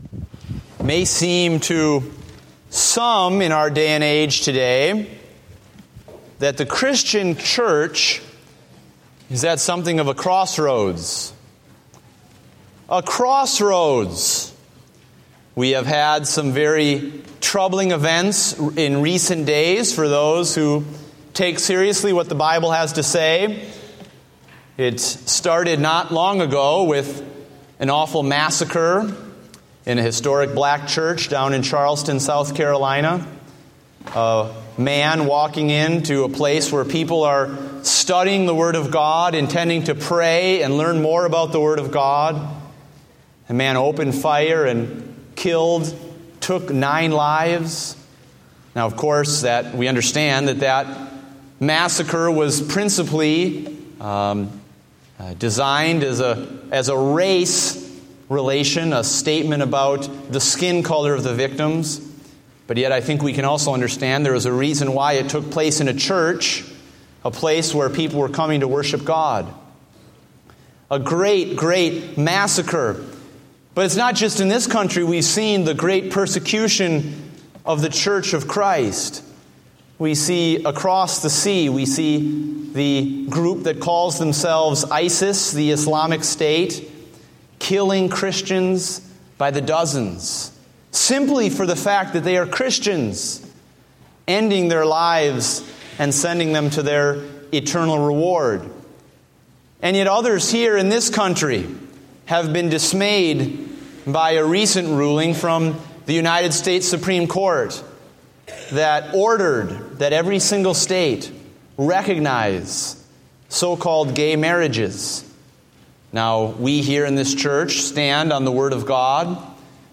Date: July 5, 2015 (Morning Service)